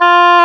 Index of /90_sSampleCDs/Roland LCDP04 Orchestral Winds/WND_Oboe 9-13/WND_Oboe Short
WND OBOE-B.wav